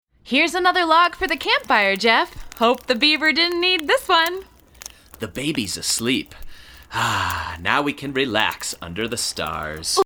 Cheerful songs jubilantly performed